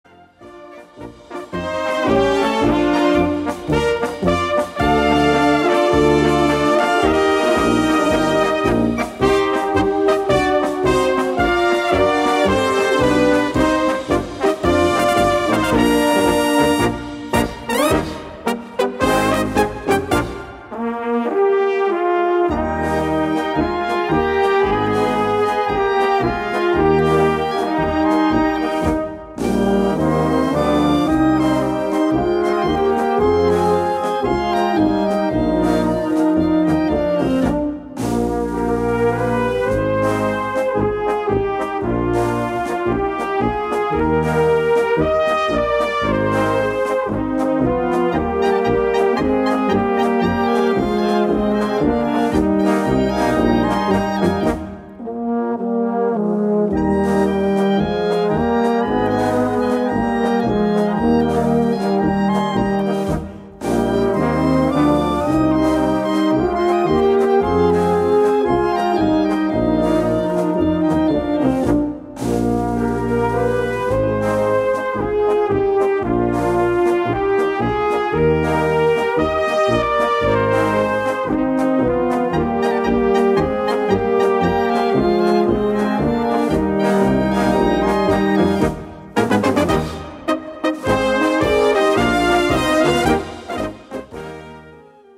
Gattung: Konzert-Polka
Besetzung: Blasorchester